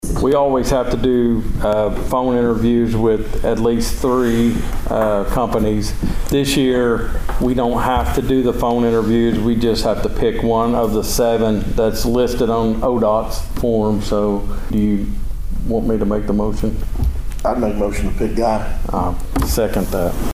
Here is District One Commissioner Anthony Hudson and District Two Commissioner
Steve Talburt making that decision.